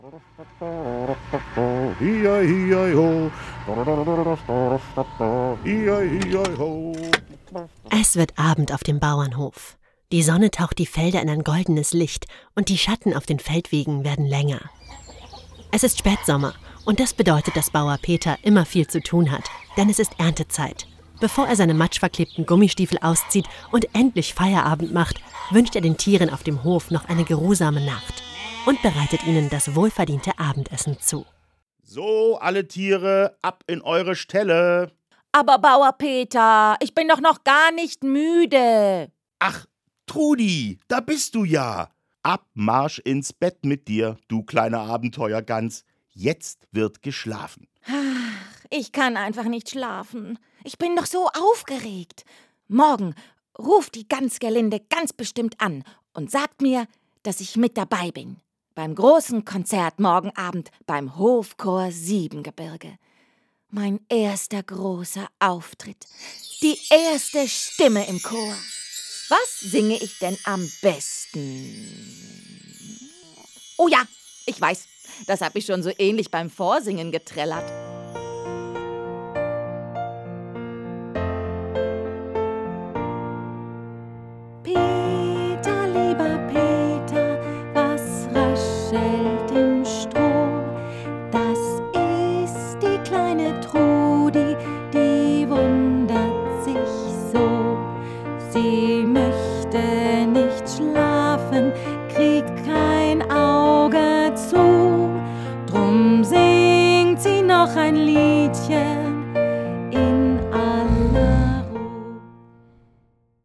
Mit der Gute-Nacht-Geschichte „Schlaf gut, Trudi“ als Hörspiel mit Musik finden die Kleinen vom aufregenden Tag sanft in den Schlaf. Erzählt wird der Abend vor dem Theaterstück „Trudi – Die Gans vom Gertrudenhof“.
• Klavierbegleitung
• Gesang